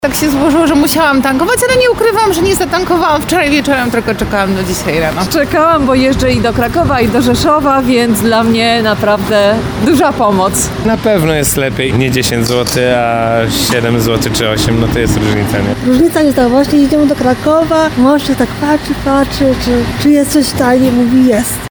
Kierowcy w Tarnowie przyznają, że w ostatnich dniach wstrzymywali się z tankowaniem i czekali na obniżki cen.
31sonda-paliwo.mp3